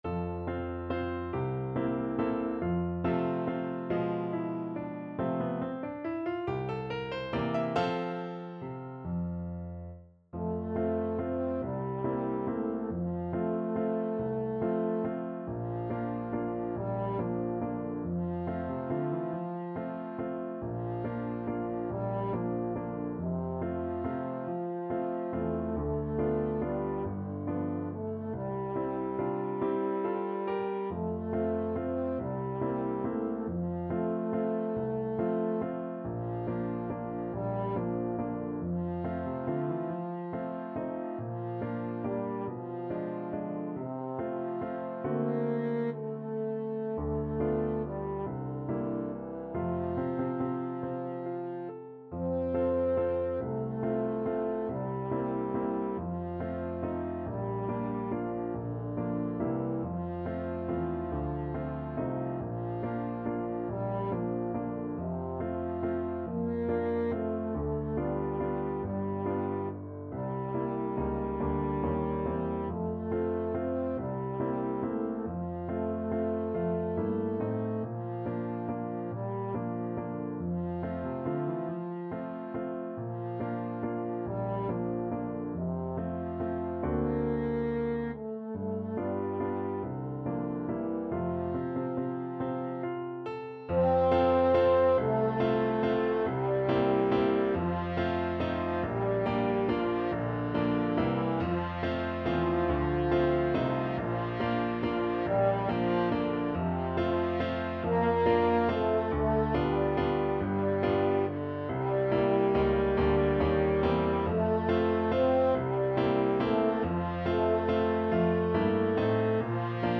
French Horn
3/4 (View more 3/4 Music)
F major (Sounding Pitch) C major (French Horn in F) (View more F major Music for French Horn )
~ = 140 Tempo di Valse